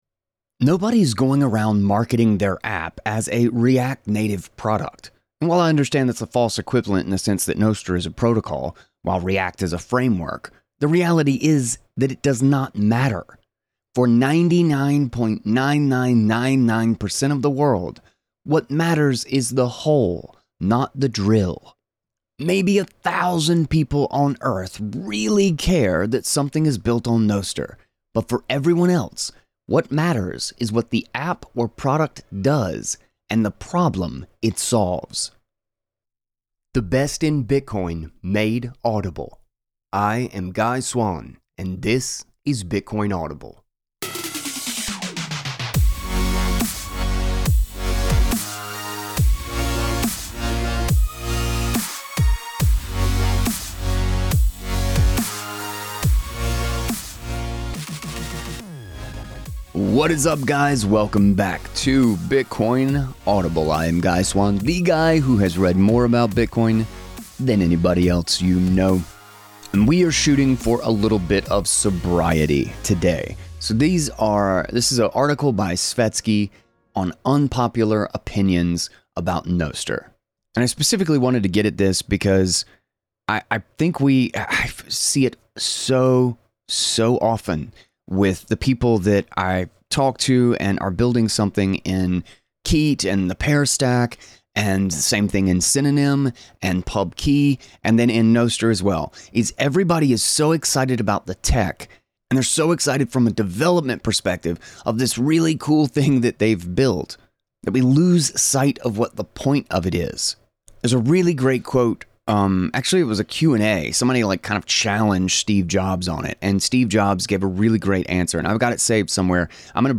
In this conversation, we unpack the hype around Nostr, the pitfalls of developer-first thinking, and the lessons Bitcoiners should learn if we want adoption that truly matters.